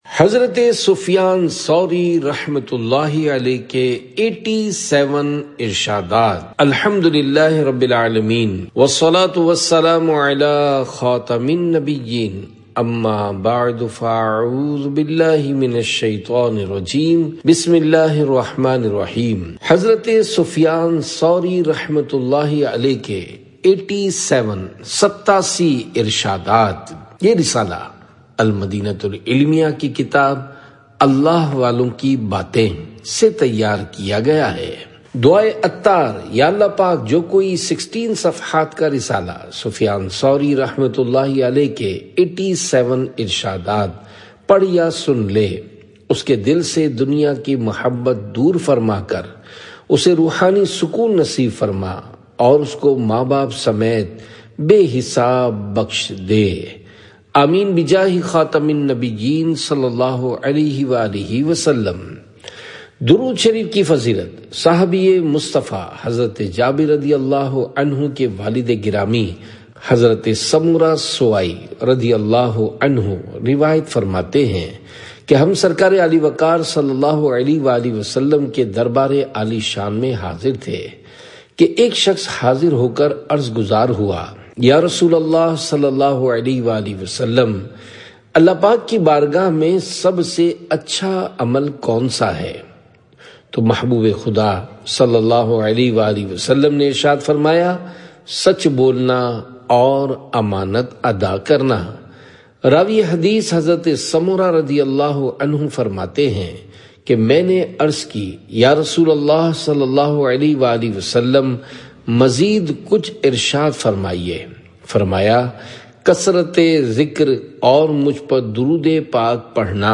Audiobook – Hazrat Sufyan Sauri رحمۃ اللہ علیہ ke 87 Irshadaat (Urdu)